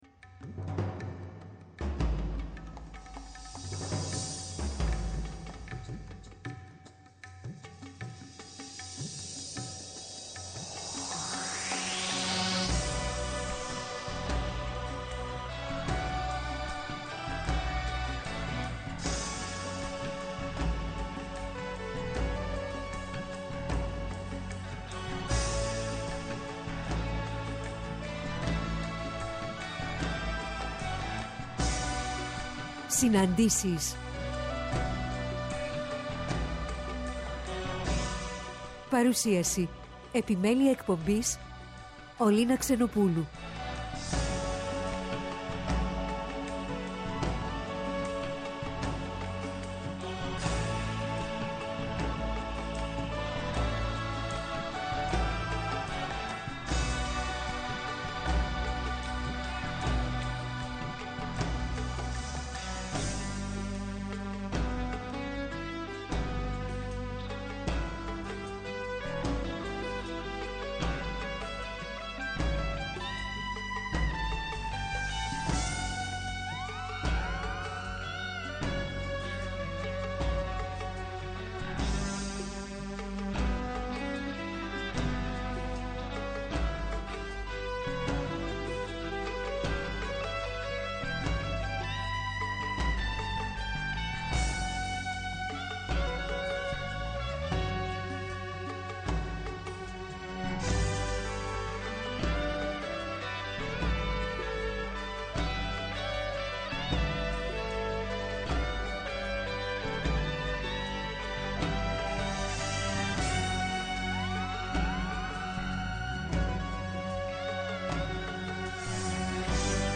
Στις «ΣΥΝΑΝΤΗΣΕΙΣ» στο Πρώτο Πρόγραμμα την Κυριακή 15-01-23 και ώρα 16:00-17:00 καλεσμένη τηλεφωνικά: